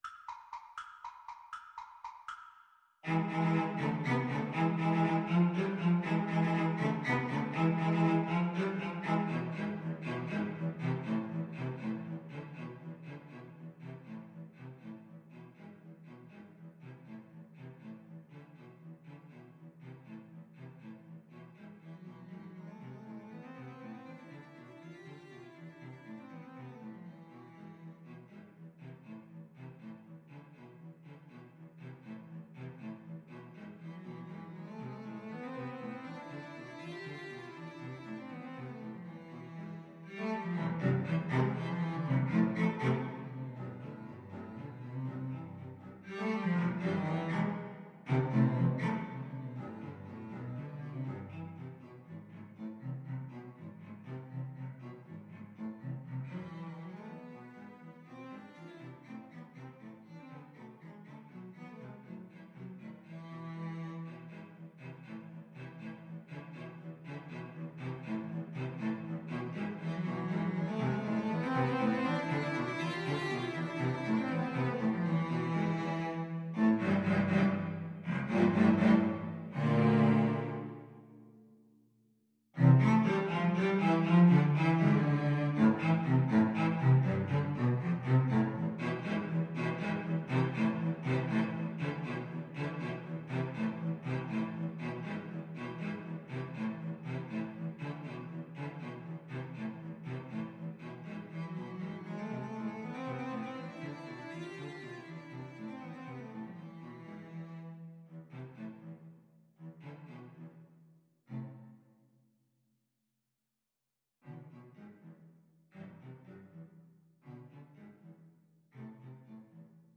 3/8 (View more 3/8 Music)
Allegro vivo (.=80) (View more music marked Allegro)
Classical (View more Classical Cello Trio Music)